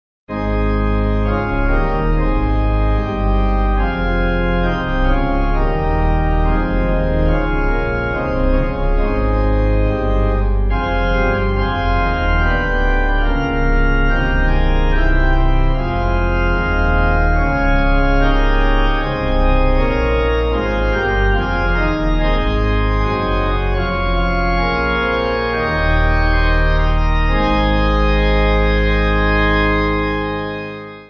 Organ
(CM)   5/Ab